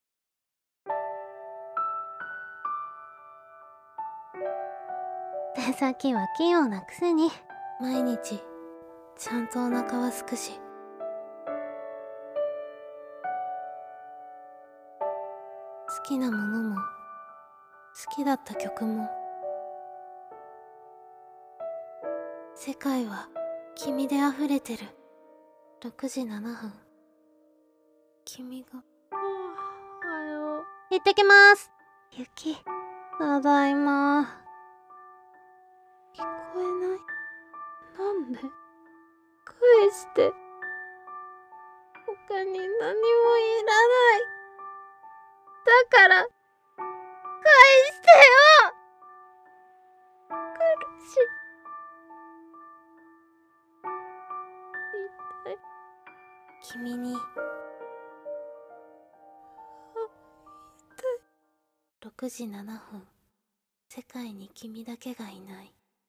【声劇】たそかれ、きみと、